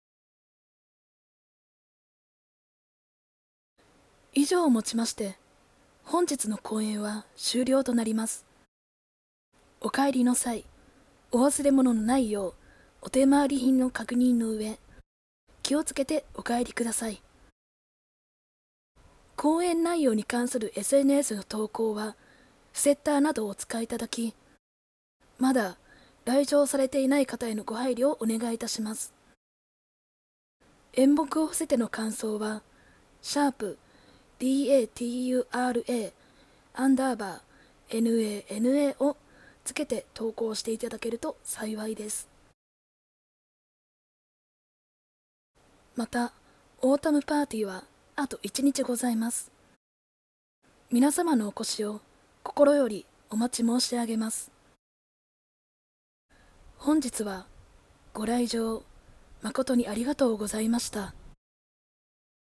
影ナレ
地声